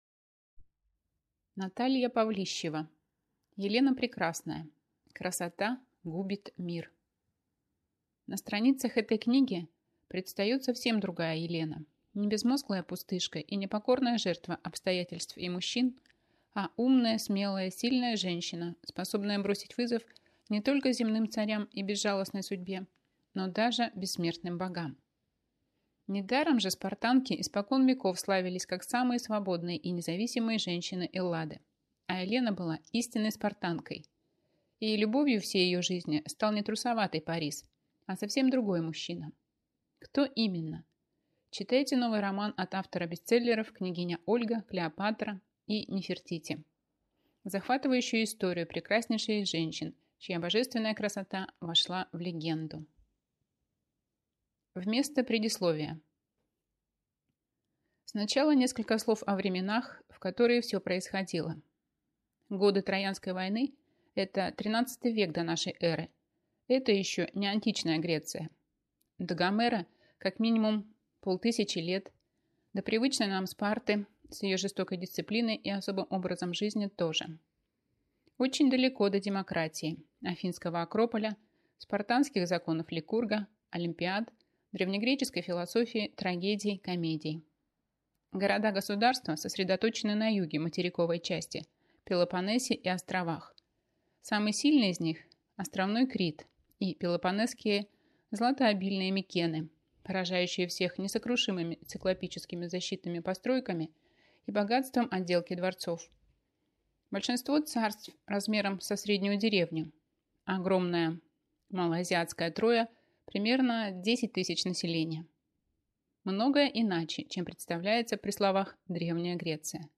Аудиокнига Елена Прекрасная. Красота губит мир | Библиотека аудиокниг